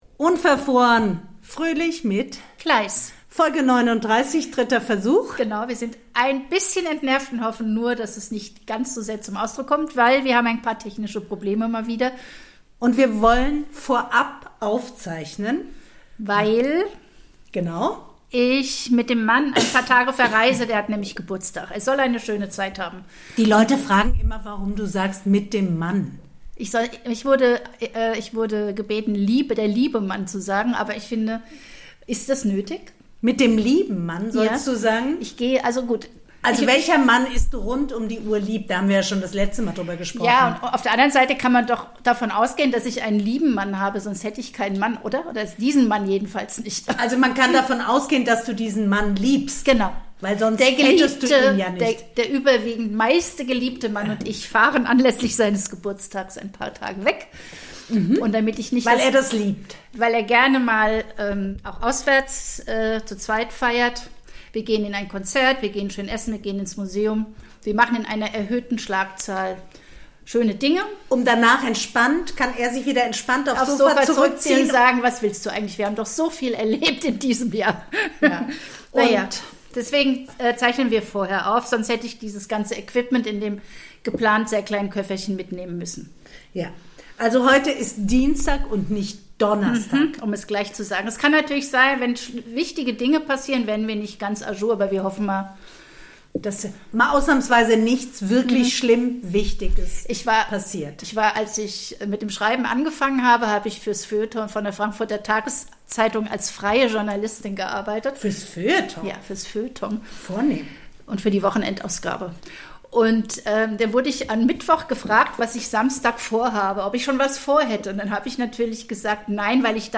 reden die beiden Podcasterinnen über Buch Verbote in den USA, über olfaktorische Zumutungen, Erinnerungslücken und niveaulose Anmache.